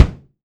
Index of /kb6/Akai_MPC500/1. Kits/Amb Rm Kit
KUMA BD1mf mx2.WAV